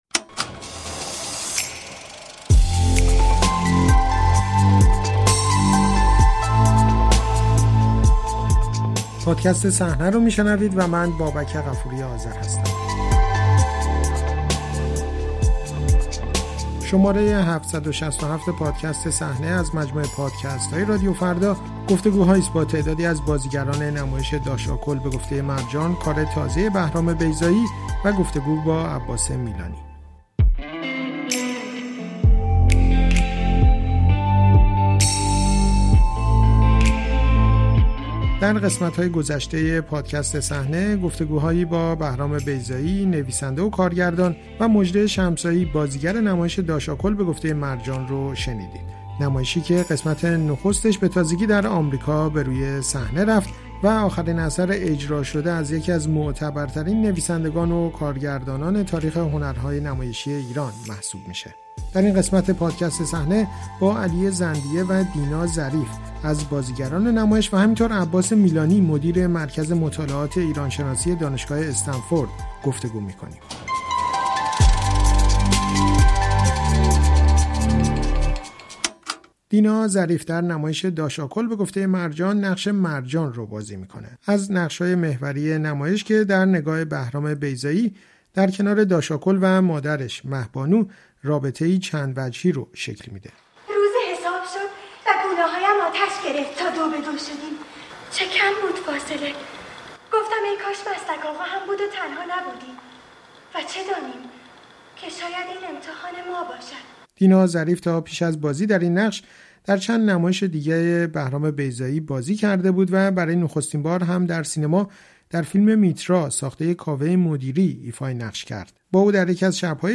در این شماره صحنه همچنین با عباس میلانی، مدیر مرکز مطالعات ایرانشناسی دانشگاه استنفورد هم درباره تدریس بهرام بیضایی در این مرکز و حمایت از اجرای نمایش‌های این نام معتبر فرهنگ و هنر ایران در آمریکا گفت‌وگو شده است. آقای میلانی می‌گوید بهرام بیضایی در مدت اقامت در آمریکا بیش از تمام زمان‌های حضورش در ایران نمایش بر صحنه برده است.